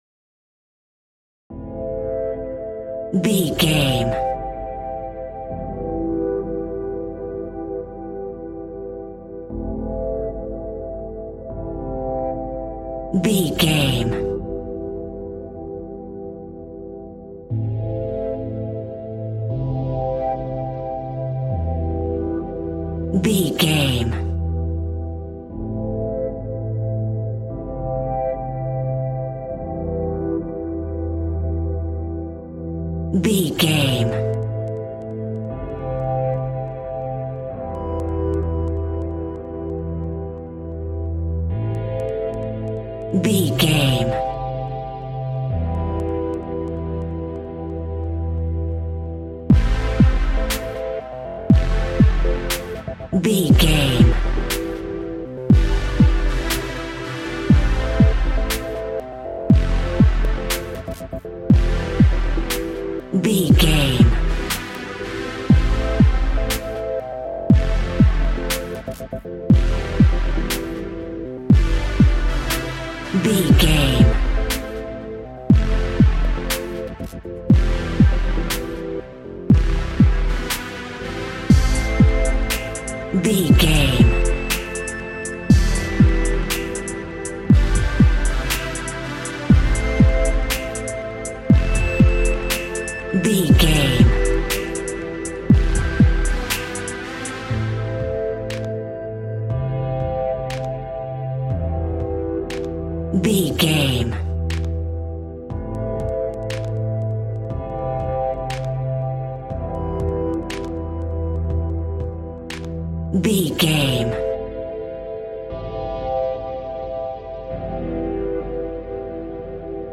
Aeolian/Minor
Slow
groovy
dreamy
tranquil
smooth
futuristic
drum machine
synthesiser
chillwave
synthwave
synth leads
synth bass